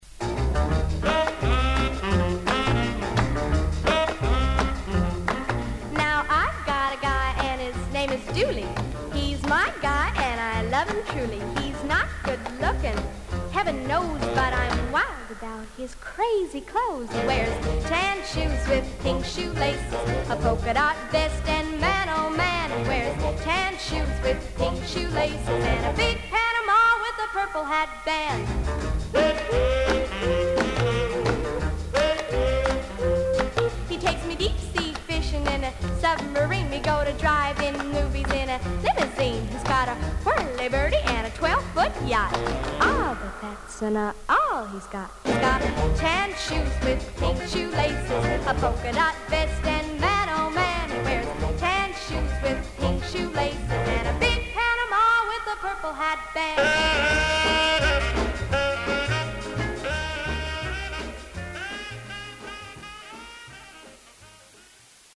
VG++ 少々軽いパチノイズの箇所あり。きれいな音で鳴ります。 黒ラベル ガール・シンガー。